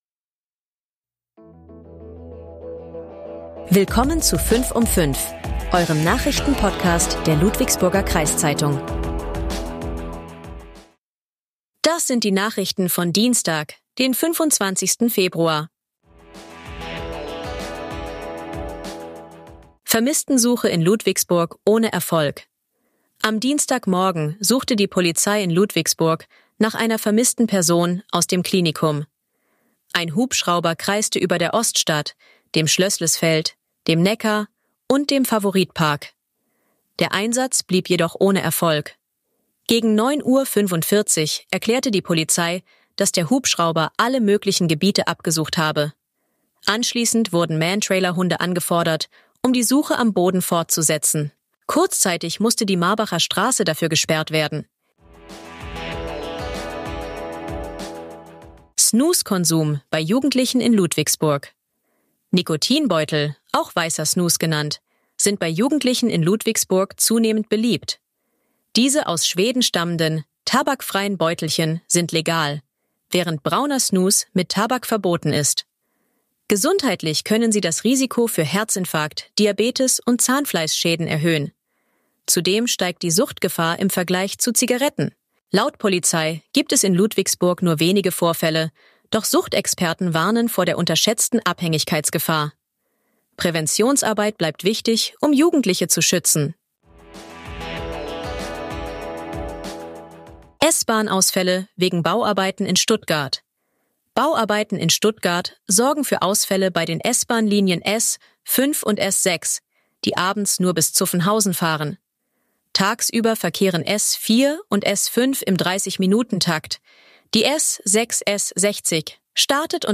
Euer Nachrichten-Podcast der Ludwigsburger Kreiszeitung